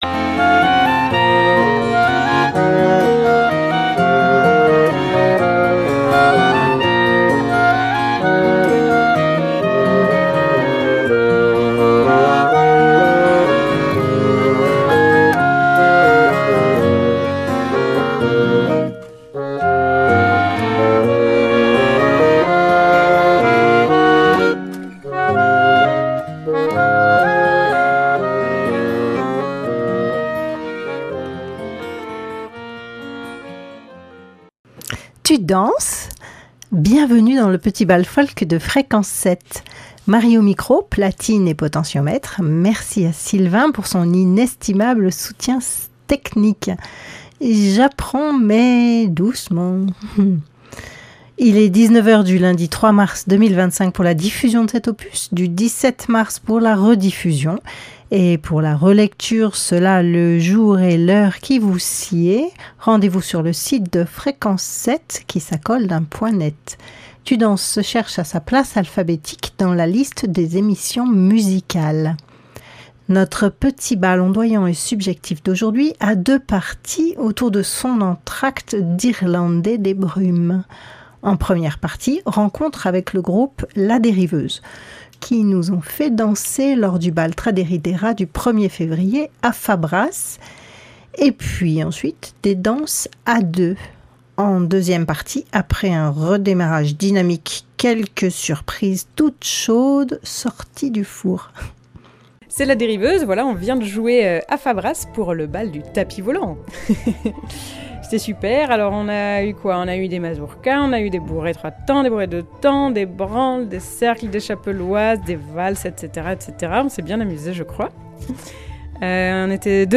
Petit bal folk subjectif, improbable, interplanétaire et délicieux. De la musique pour danser, des entretiens avec des musiciens ou des danseurs, quelques notes de l'actualité des bals, et un entracte pour souffler ou rêver.